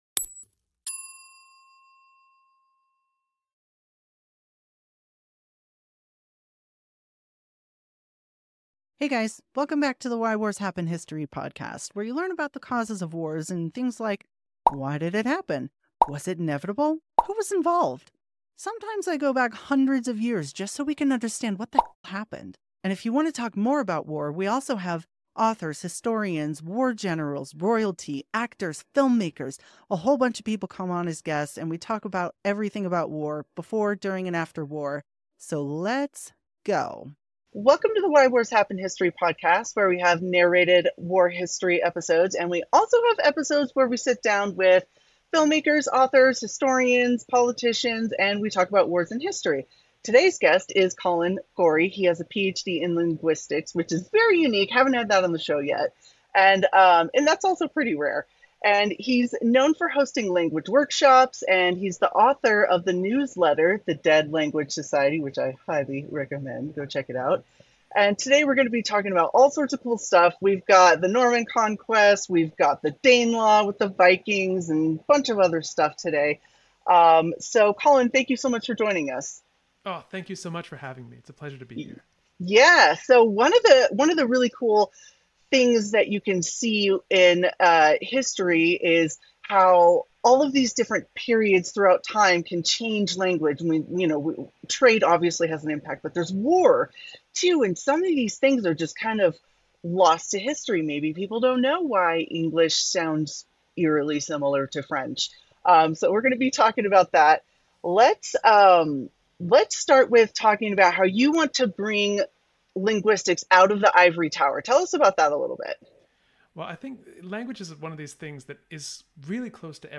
1 Three Moves Ahead 637: Stormgate: Ashes of Earth Interview 1:01:27